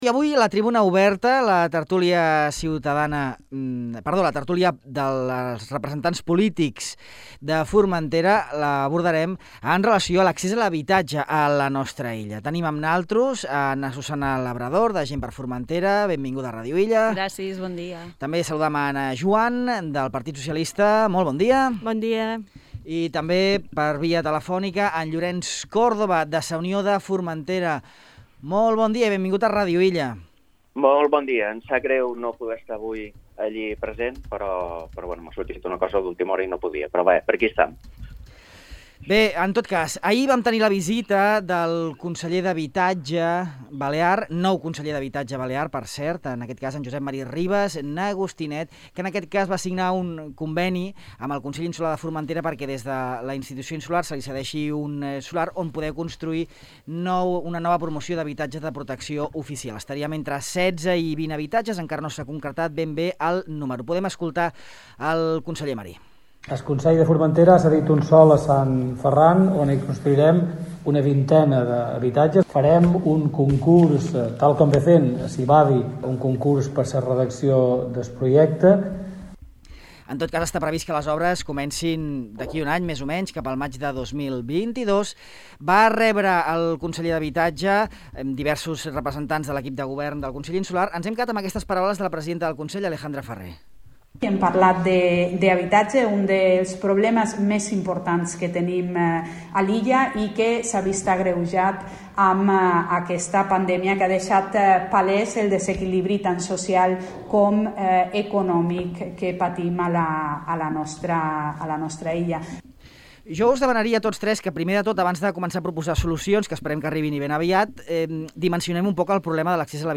L'accés a l'habitatge a la tertúlia política
Ana Juan, del PSOE; Llorenç Córdoba, de Sa Unió de Formentera; i Susana Labrador, de Gent per Formentera, debaten en una nova edició de Tribuna Oberta sobre un dels grans problemes que afecta a la societat illenca, l’accés a l’habitatge.